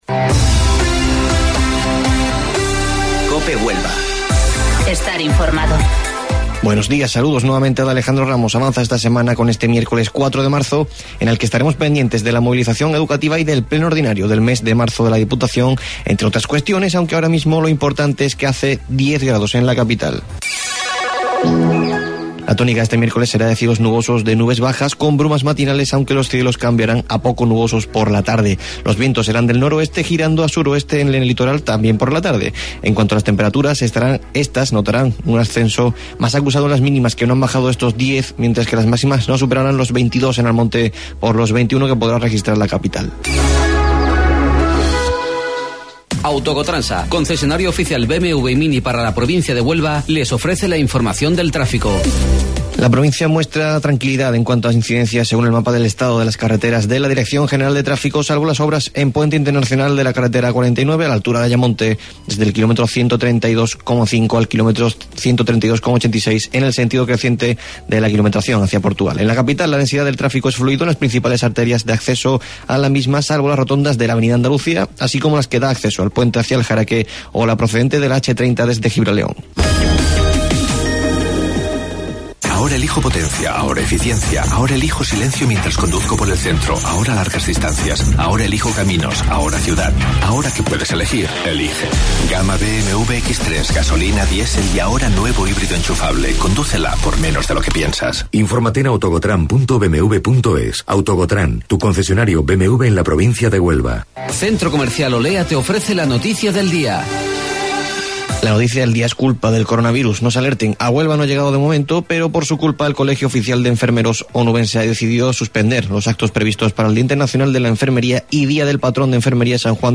AUDIO: Informativo Local 08:25 del 4 Marzo